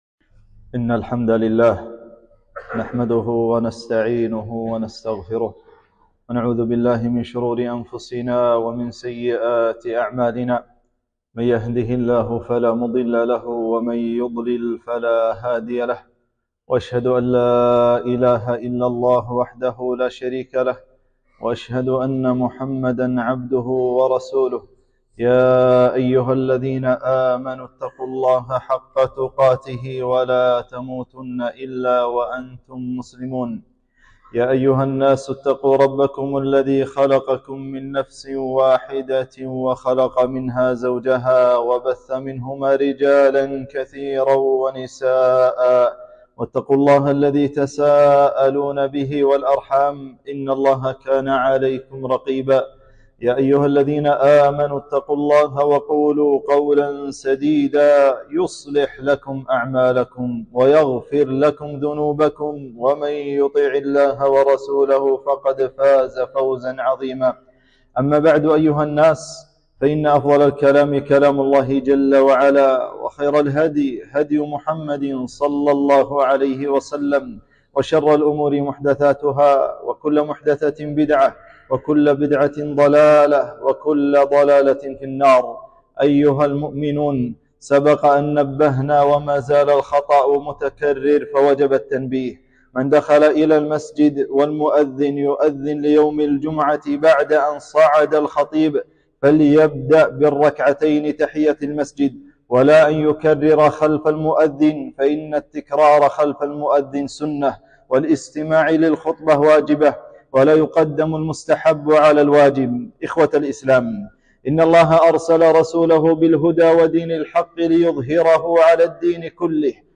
خطبة - أنواع الهداية وأهميتها